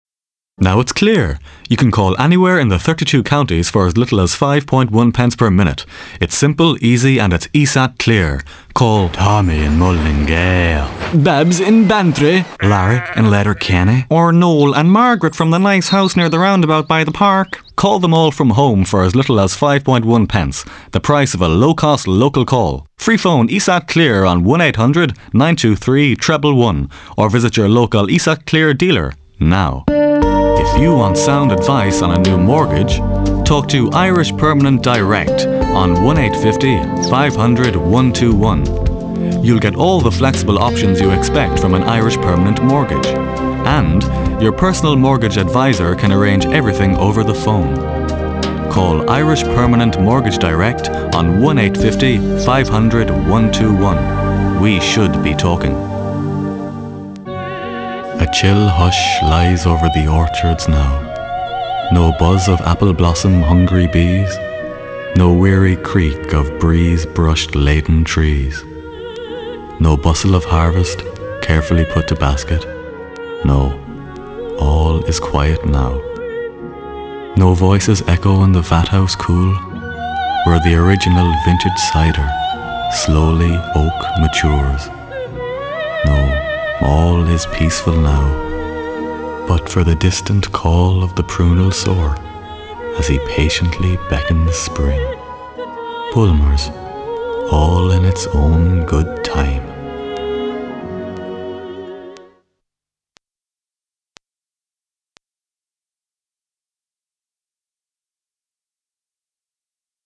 A deep voice, rich in tone.
Voice Reel